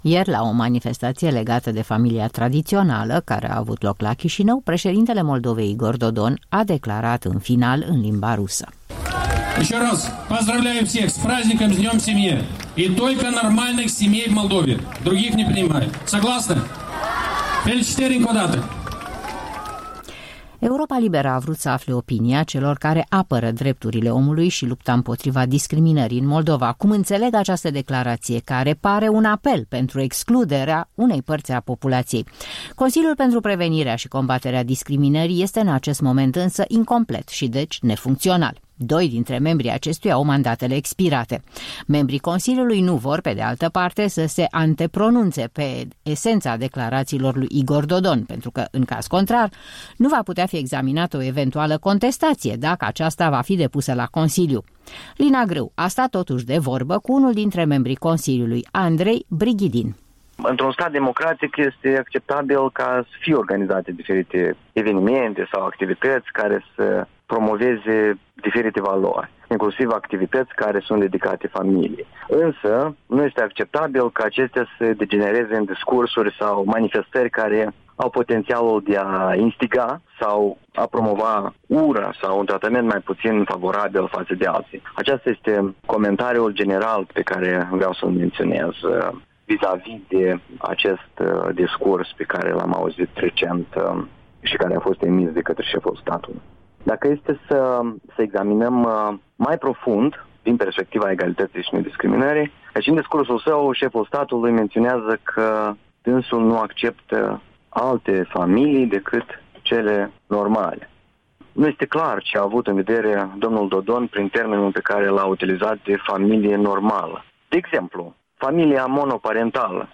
Un interviu cu un membru al Consiliului pentru prevenirea și eliminare discriminării și asigurarea egalității, pe marginea discursului președintelui Igpr Dodon.